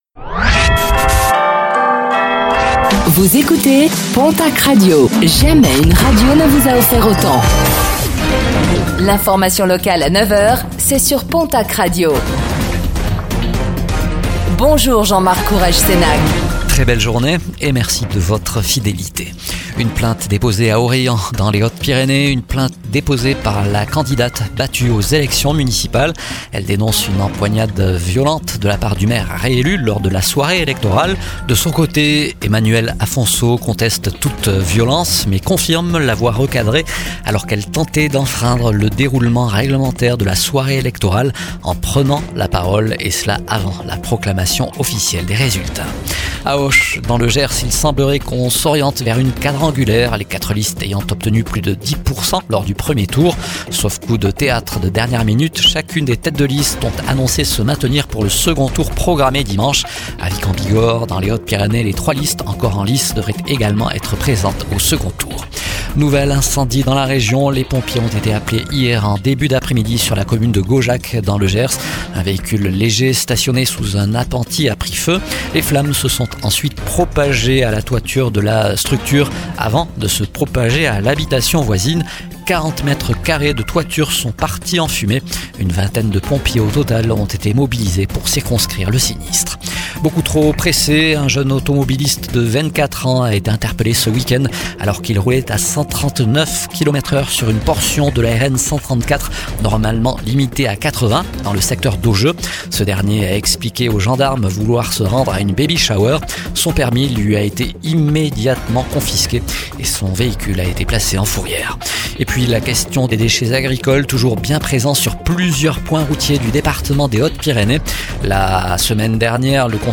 Réécoutez le flash d'information locale de ce mardi 17 mars 2026